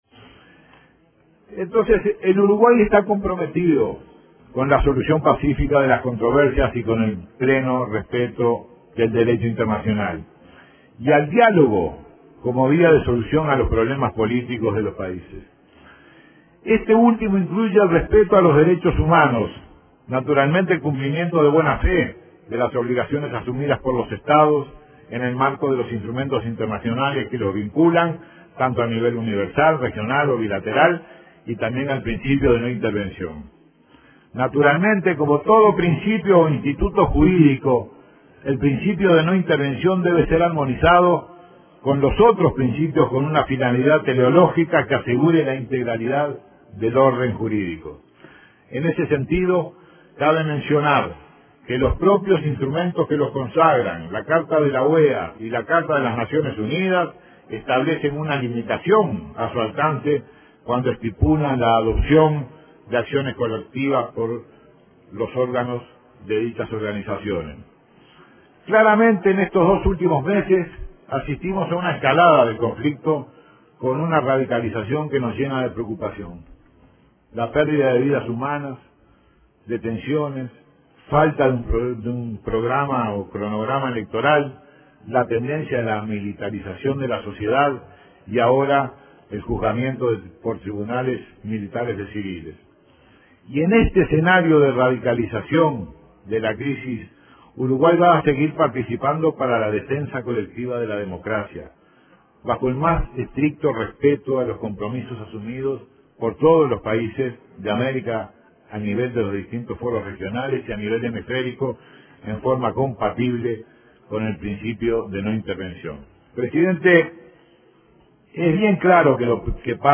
“El principio de no intervención mantiene su plena vigencia y está inscripto en la roca de la política exterior uruguaya”, afirmó el canciller Rodolfo Nin Novoa, este miércoles en un llamado a sala en la Cámara de Representantes por la situación en Venezuela. “No pensamos que sea una sabia decisión aislar o sancionar a Venezuela”, recalcó.